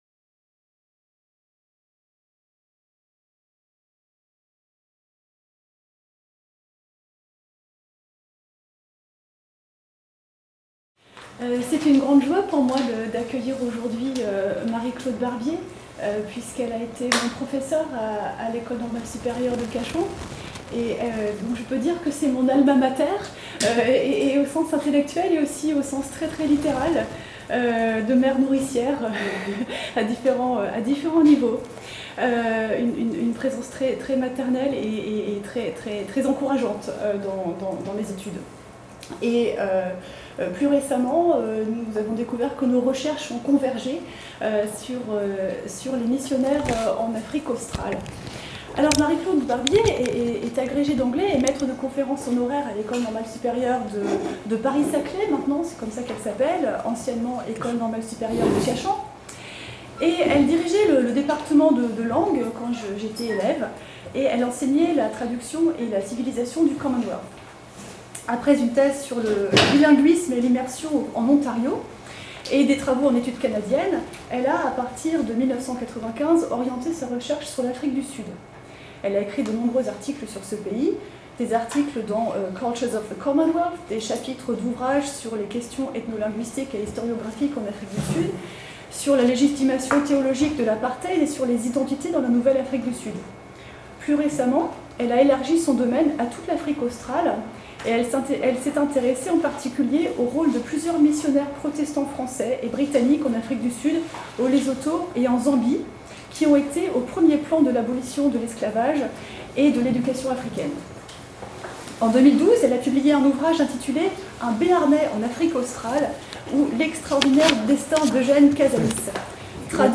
Séminaires ERIBIA 2018-2019 : Mémoires et oublis / Memory and Oblivion